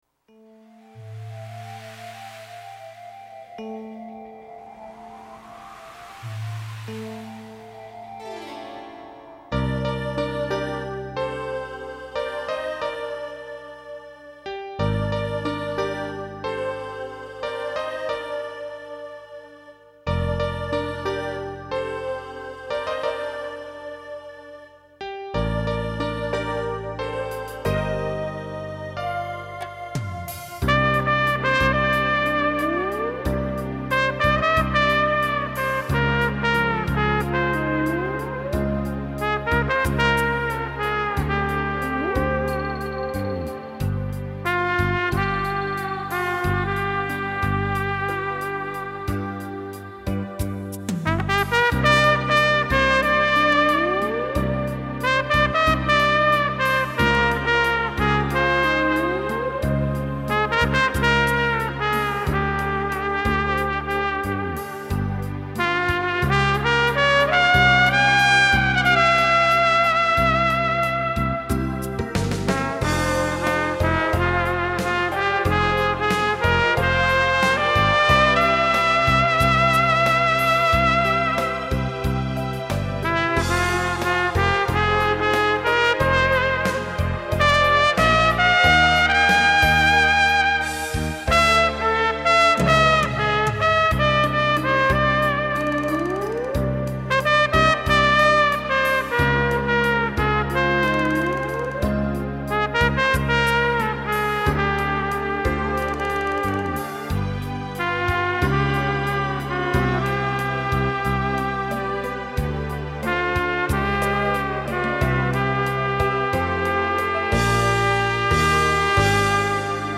とてもロマンチックで幻想的で・・・ブランデーを片手に深夜何回も聴かせていただいております。